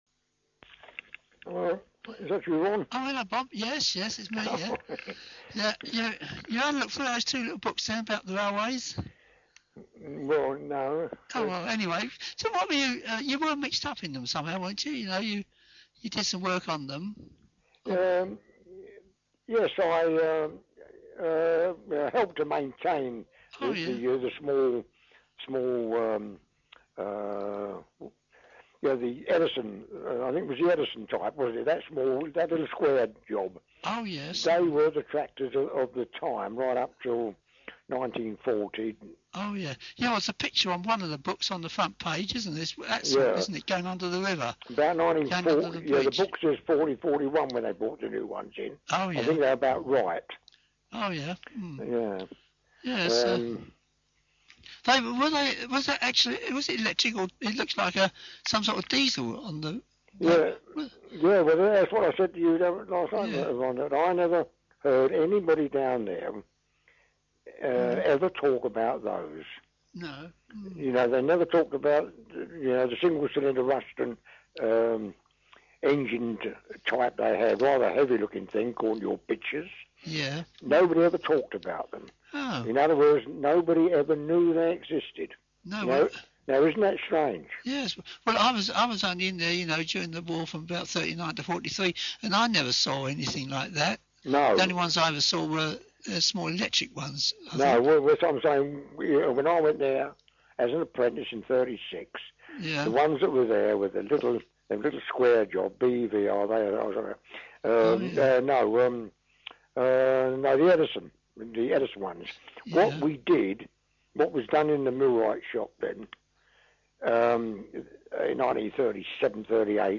Oral History Interviews with Past Employees